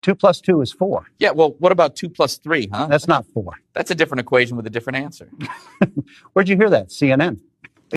Guy asks: “2+3?” … then immediately questions the answer like it’s breaking news 😂. In this economy, even basic arithmetic isn’t safe from the debate table 💀.